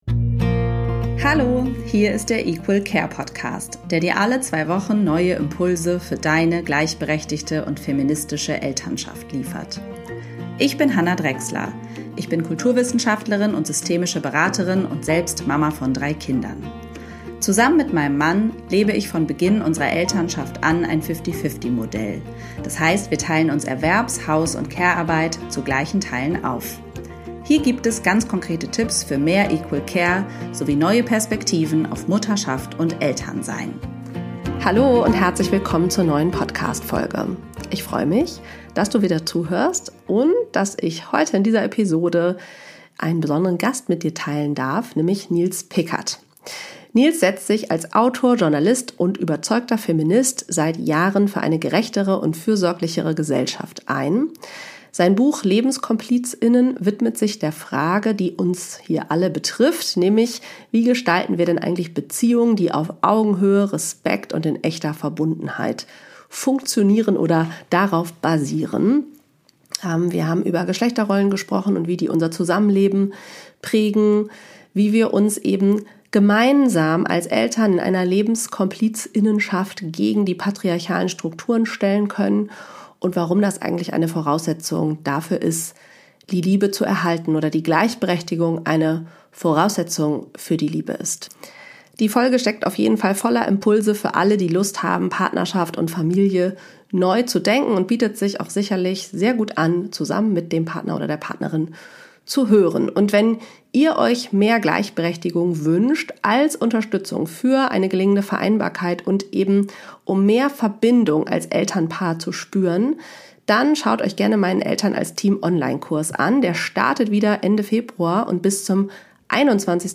Live Aufnahme Talkshow